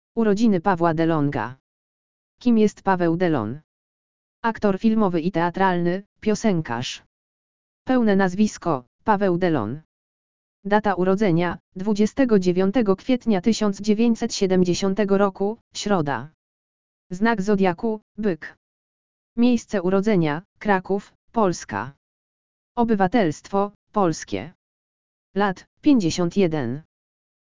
audio_lektor_urodziny_pawla_delaga.mp3